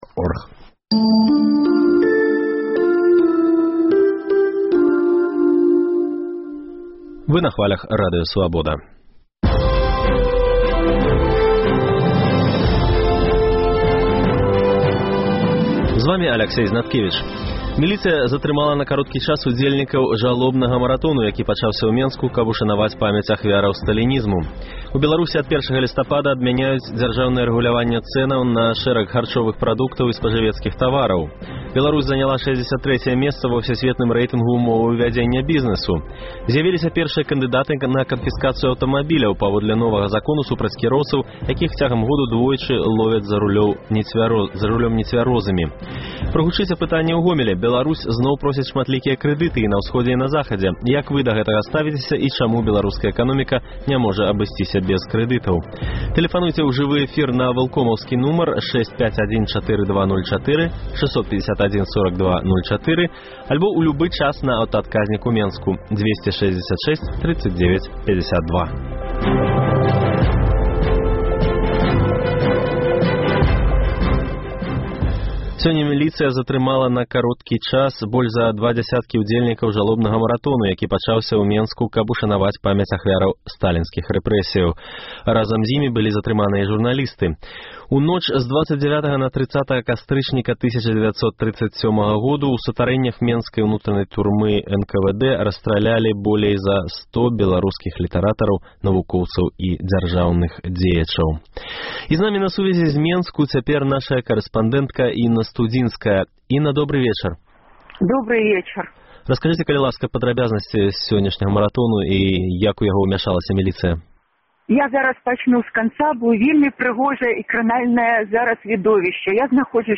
Жывы рэпартаж з жалобнага маратону